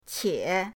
qie3.mp3